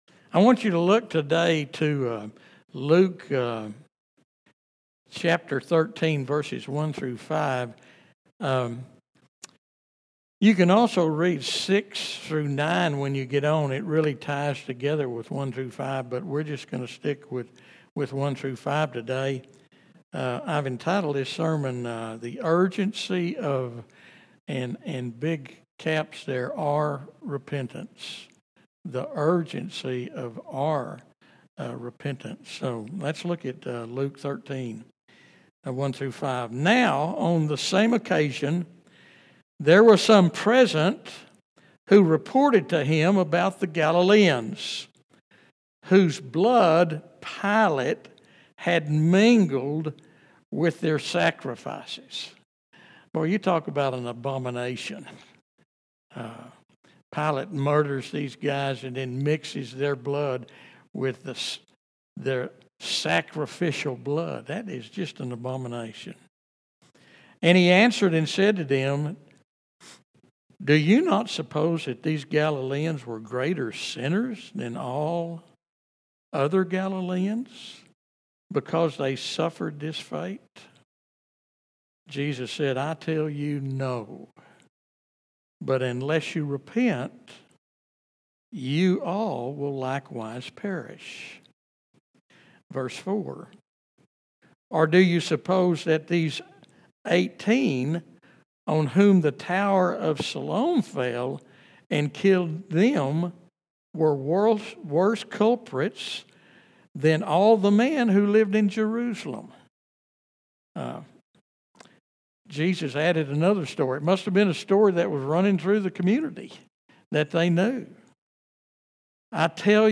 Whether you're walking through tragedy, struggling with guilt, or simply need a reminder of God's patient love, this sermon offers hope and practical wisdom for bearing fruit in your spiritual journey.